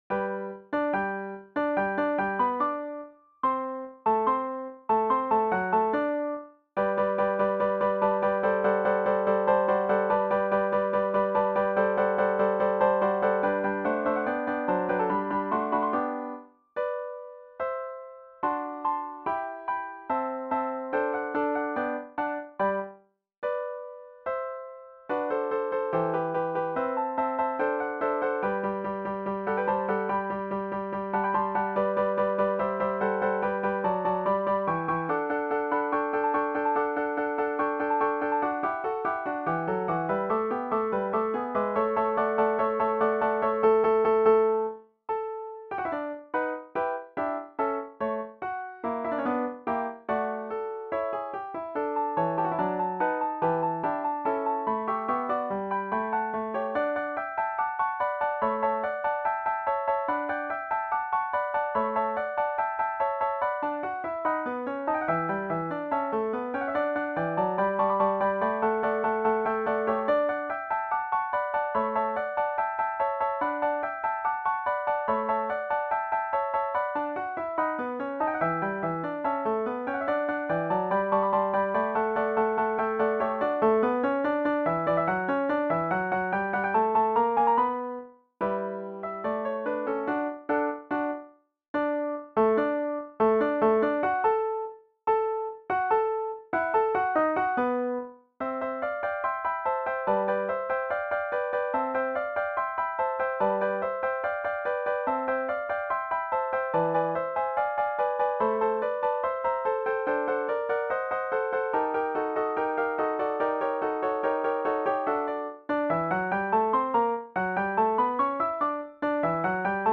arranged for four guitars